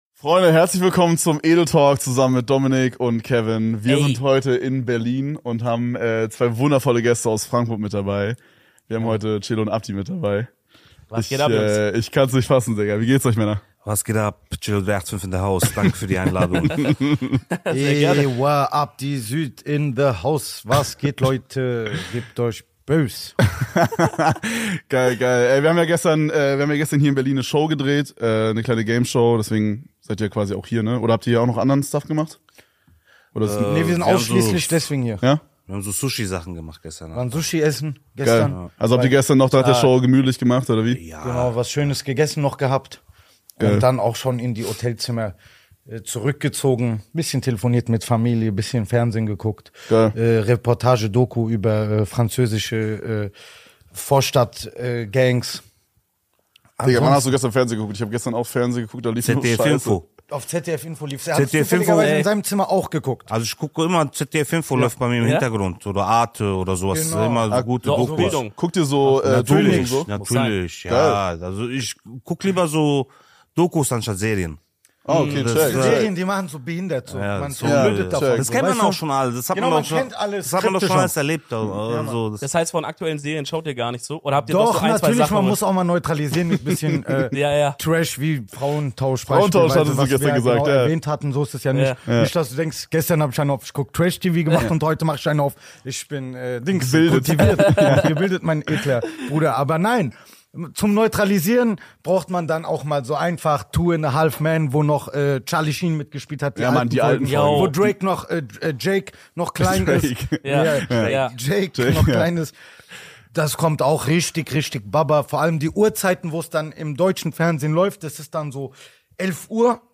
Heute sind wir in Berlin und haben in unserem Hauptstadtstudio Celo & Abdi zu Gast.